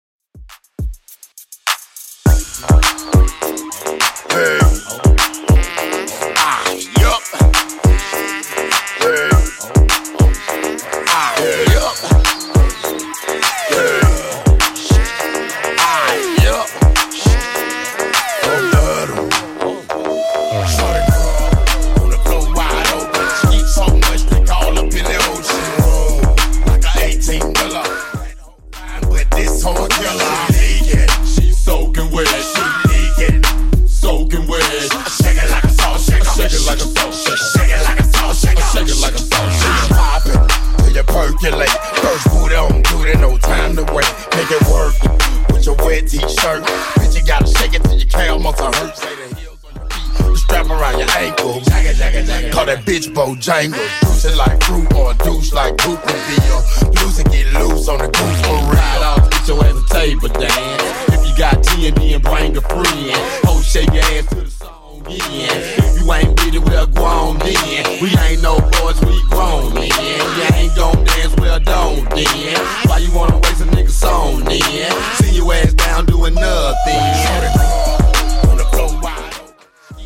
Genre: 2000's
BPM: 102